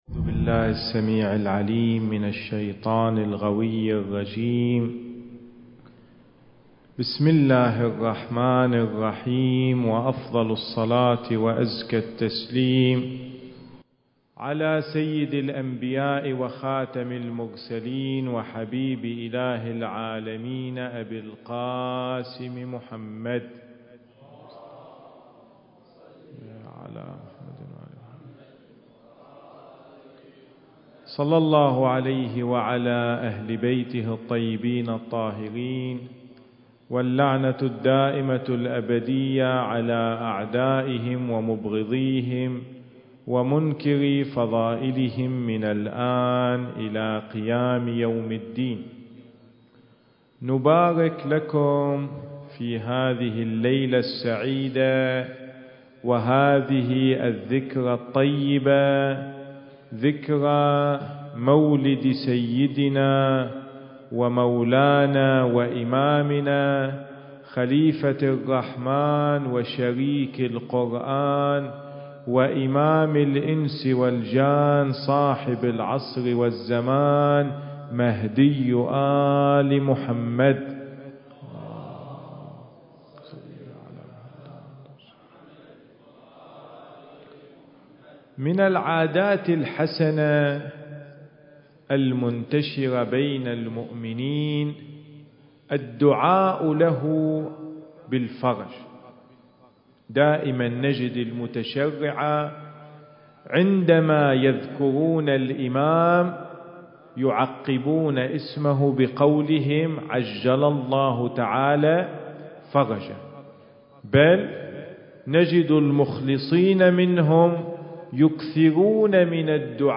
المكان: حسينية الناصر بسيهات التاريخ: 15 شعبان/ 1437 للهجرة